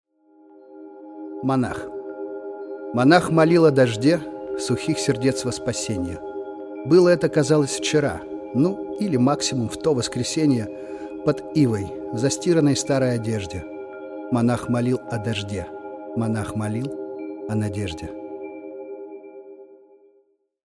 Жанр: Жанры / Поп-музыка